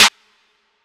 SNARE 31.wav